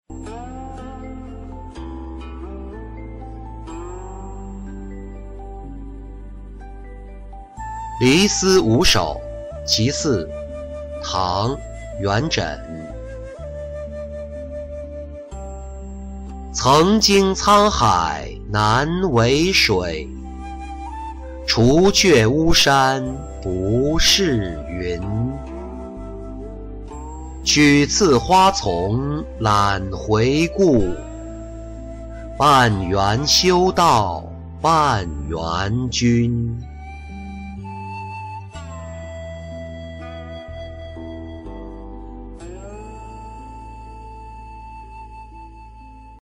离思五首·其四-音频朗读